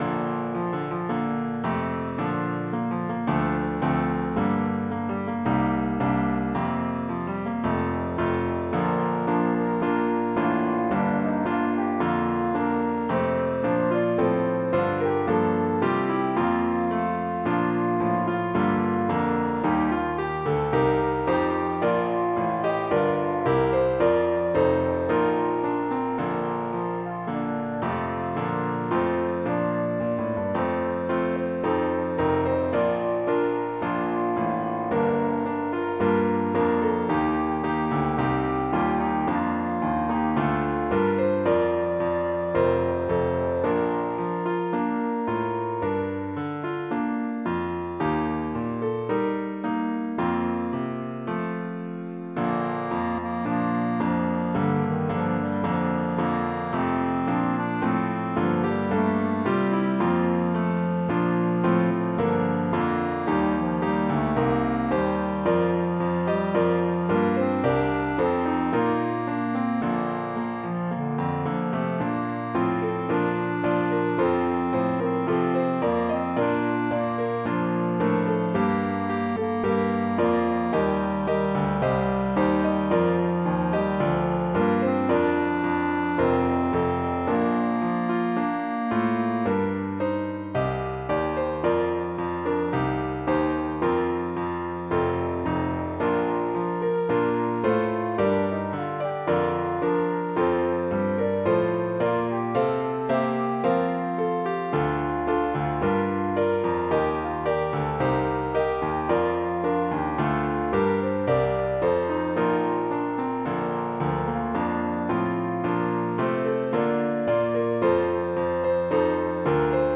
This rousing arrangement
EFY style/Contemporary